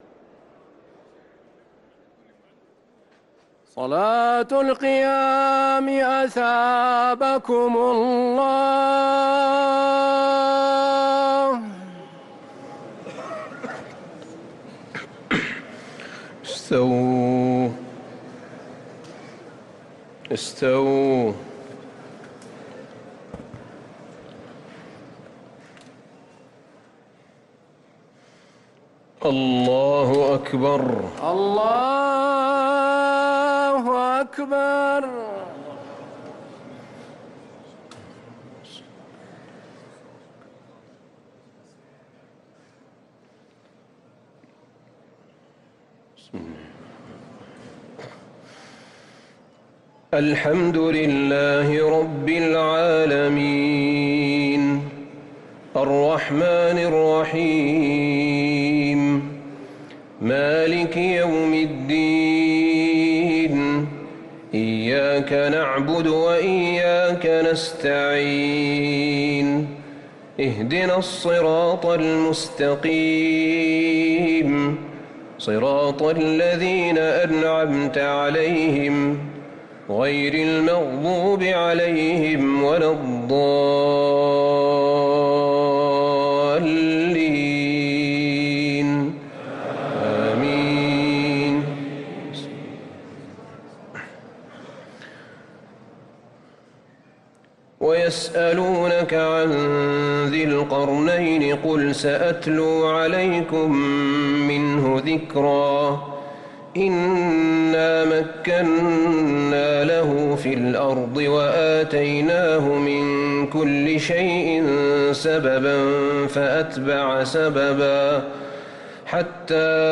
صلاة التراويح ليلة 21 رمضان 1444 للقارئ أحمد بن طالب حميد - الثلاث التسليمات الأولى صلاة التراويح